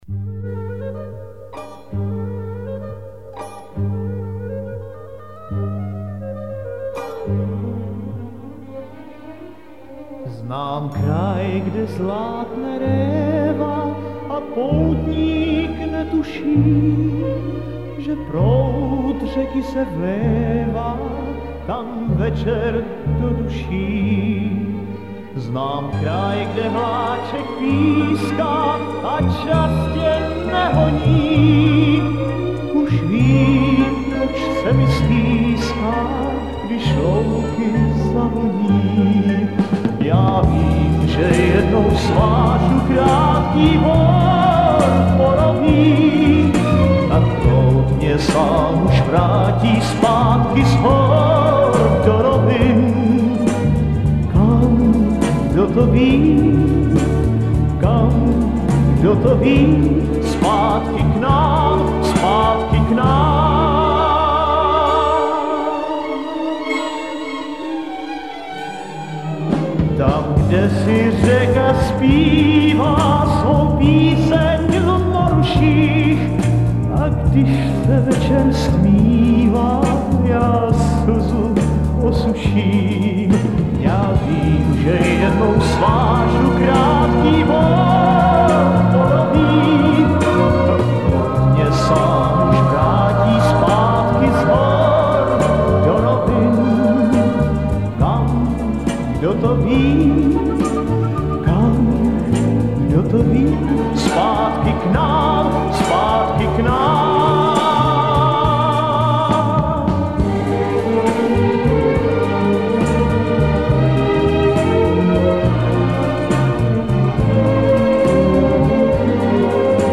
симфоническая поэма